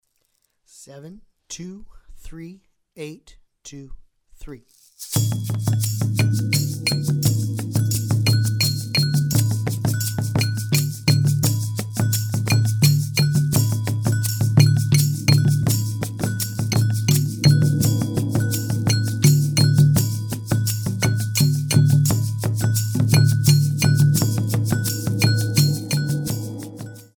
The music combines various percussion instruments,
Slow Triple Meter
Slow Triple Meter - 85 bpm